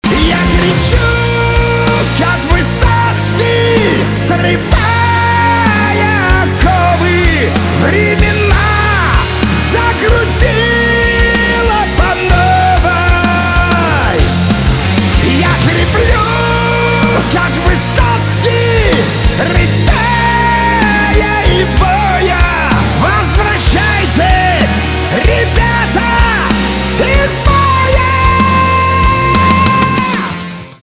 поп
грустные , чувственные
гитара , барабаны